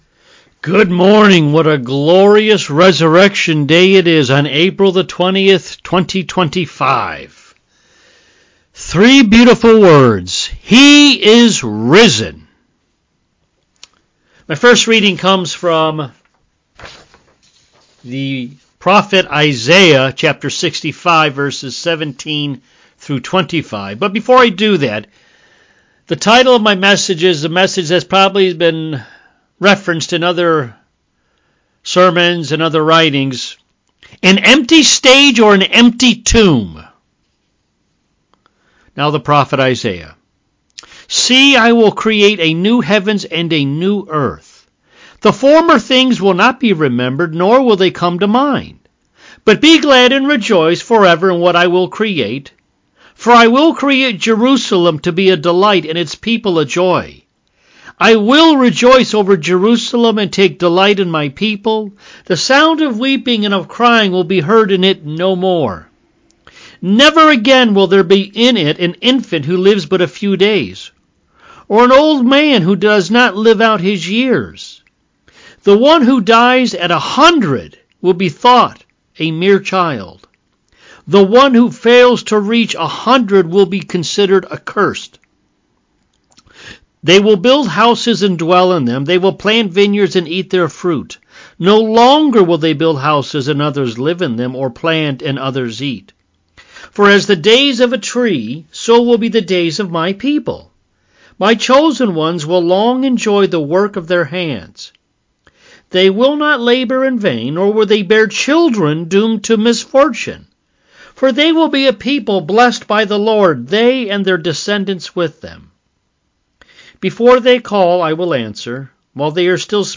Easter Sunday